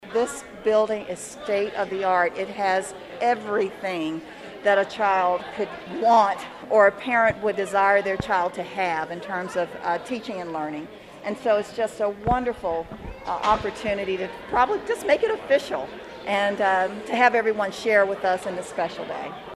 Geary County Schools USD 475 hosted a ribbon cutting and dedication ceremony for the 437,000 square foot facility.